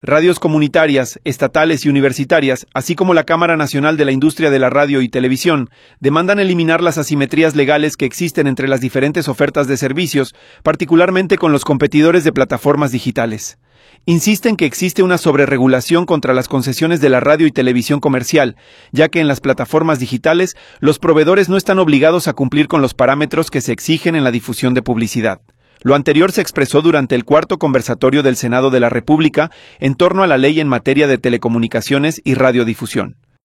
Insisten que existe una sobrerregulación contra las concesiones de la radio y televisión comercial, ya que en las plataformas digitales los proveedores no están obligados a cumplir con los parámetros que se exigen en la difusión de publicidad. Lo anterior se expresó durante el Cuarto Conversatorio del Senado de la República en torno a la Ley en Materia de Telecomunicaciones y Radiodifusión.
conversatorio.m4a